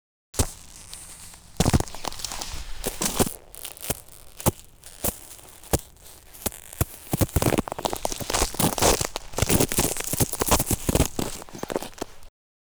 • snow samples - stepping and crushing 3.wav
Collection of recordings on January 10th 2011 during the Georgia winter storm. Various sleds, ice breaking, ice creaking, icy tension cracks, and heavy snow crunches/impacts.
snow_samples_-_stepping_and_crushing_3_dnS.wav